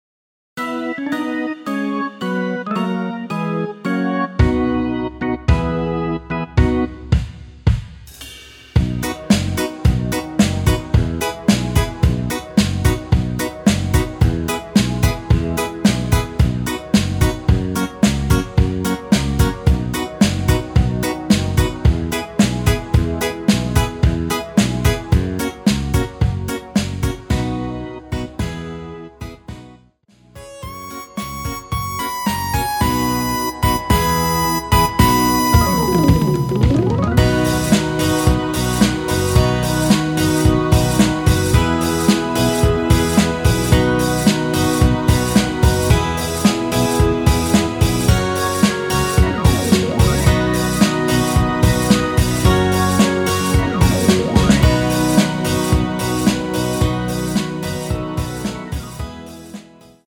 음정은 반음정씩 변하게 되며 노래방도 마찬가지로 반음정씩 변하게 됩니다.
앞부분30초, 뒷부분30초씩 편집해서 올려 드리고 있습니다.
중간에 음이 끈어지고 다시 나오는 이유는